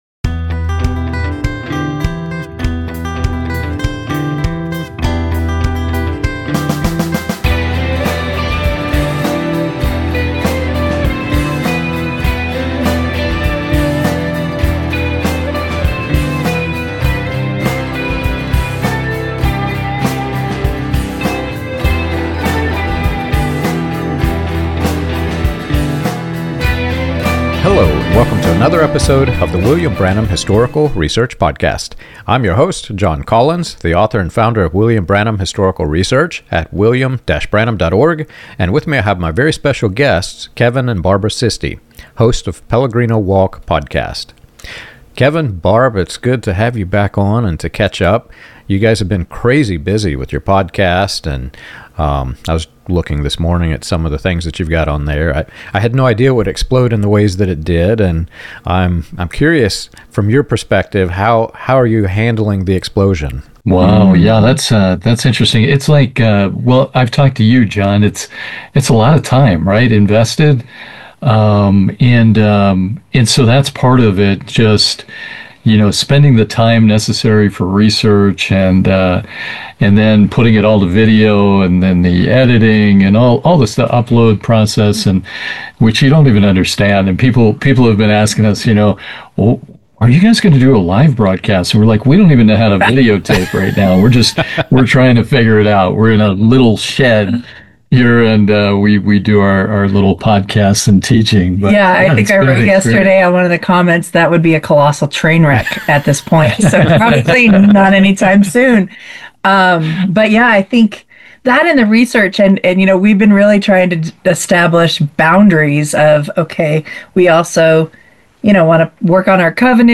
They also connect dots between revival hype, conference culture, and leadership models that reward influence without oversight. The conversation lands on practical discernment: testing fruit, refusing manipulation, rebuilding faith after spiritual harm, and keeping the God of Scripture central instead of a performance-driven substitute.